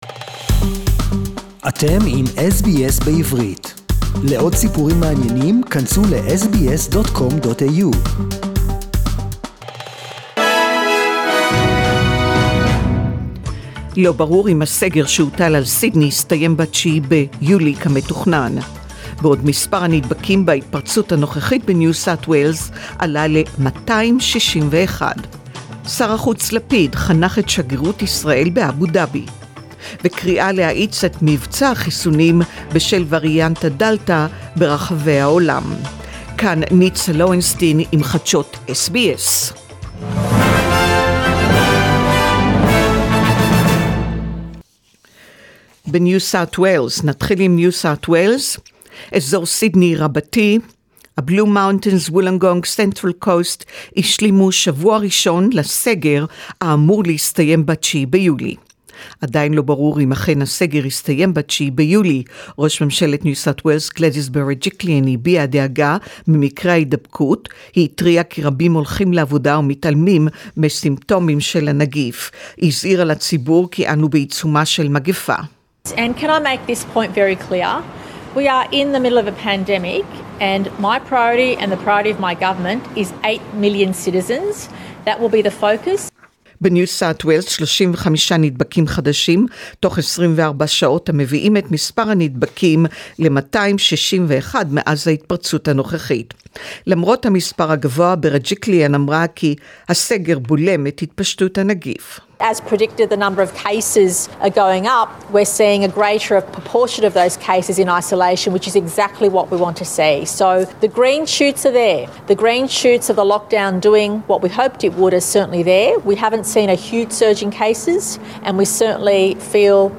SBS News in Hebrew 4.7.2021